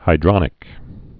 (hī-drŏnĭk)